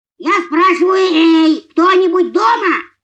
Звуки мультфильма, Винни Пух